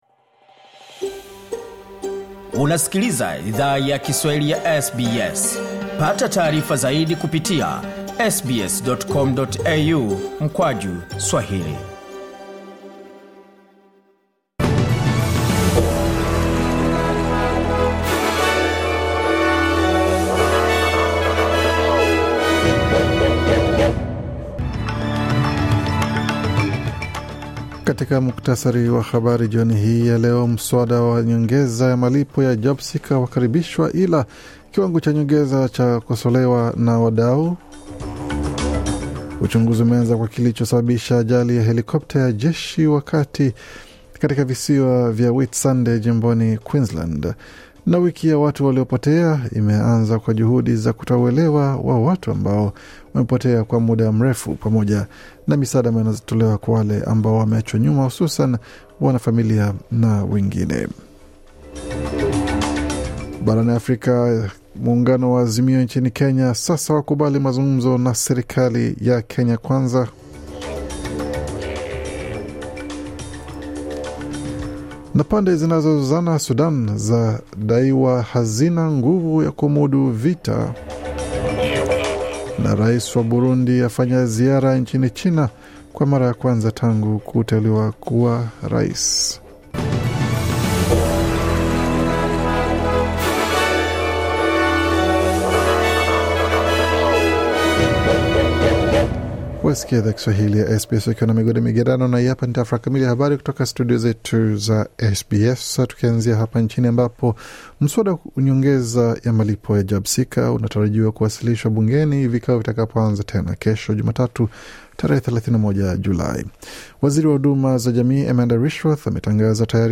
Taarifa ya Habari 30 Julai 2023